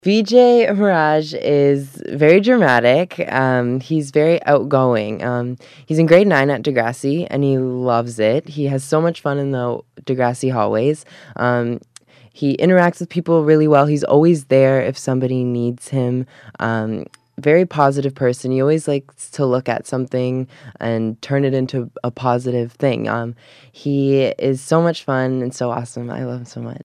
In a few highlights from the interview….